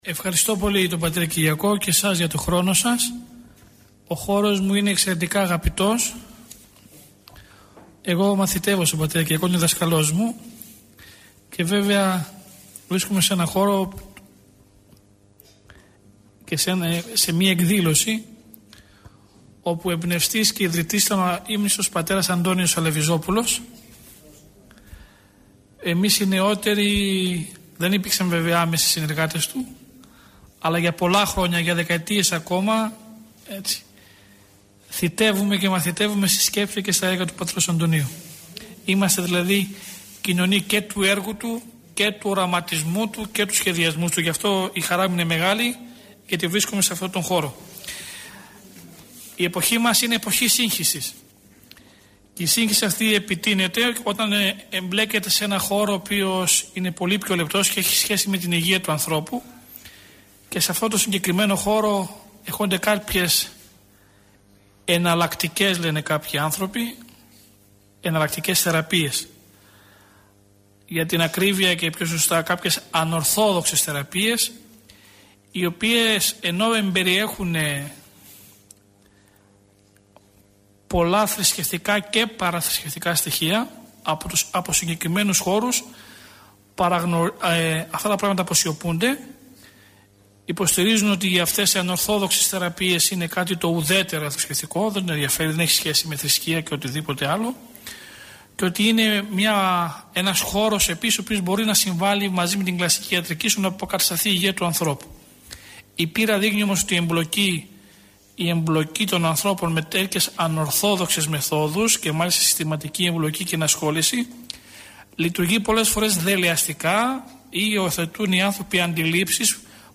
Η ομιλία αυτή “δόθηκε” στις 5 Ιουνίου του 2011, στα πλαίσια του σεμιναρίου Ορθοδόξου πίστεως – του σεμιναρίου οικοδομής στην Ορθοδοξία. Το σεμινάριο αυτό διοργανώνεται στο πνευματικό κέντρο του Ιερού Ναού της Αγ. Παρασκευής (οδός Αποστόλου Παύλου 10), του ομωνύμου Δήμου της Αττικής.
Τί είναι το ρεϊκι – ηχογραφημένη ομιλία του Πρωτ.